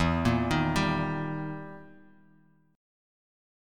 Eb-Major-E-0,1,1,0,x,x-8.m4a